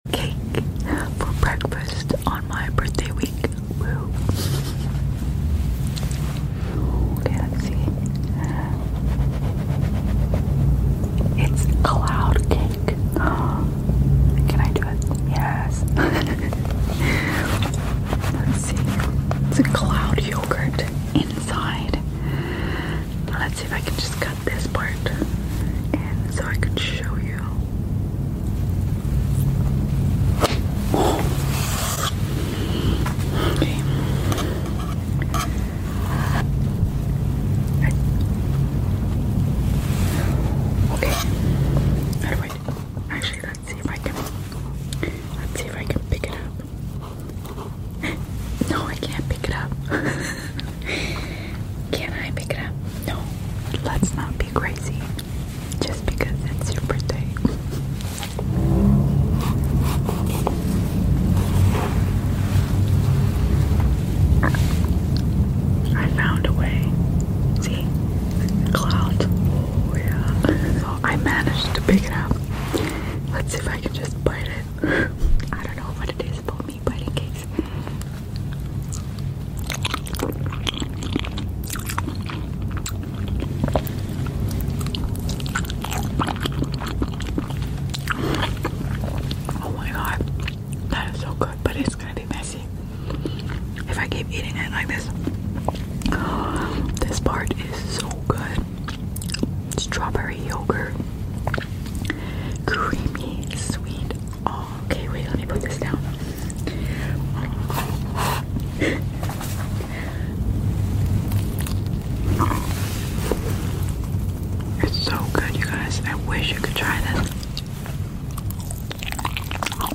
ASMR JELLO CAKE FOR BREAKFAST sound effects free download
ASMR JELLO CAKE FOR BREAKFAST P. 2 (SOFT SQUISHY EATING SOUNDS) LIGHT WHISPERS